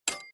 minislot_stop_3.mp3